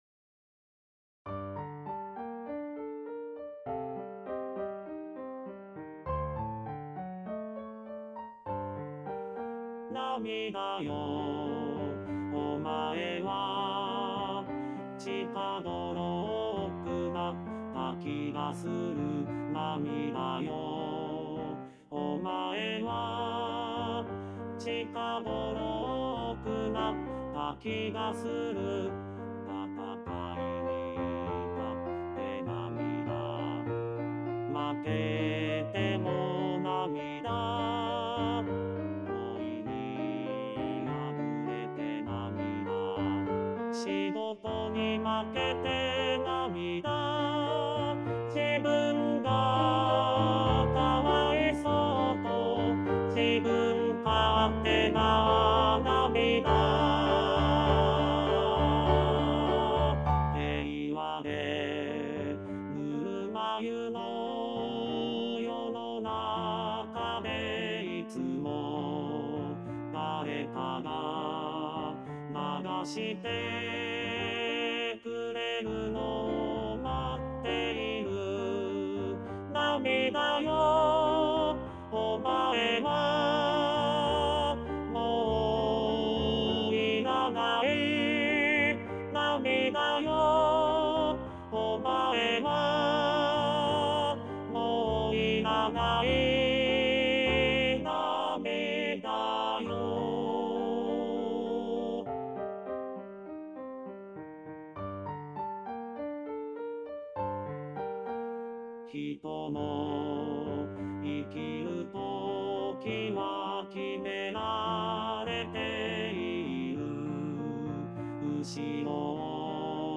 ４．さらば涙よ（男声合唱）
●パート単独音源　　　■Rchソプラノ、Lch、アルト、テノール、バス
sarabanamidayo_bassueall.mp3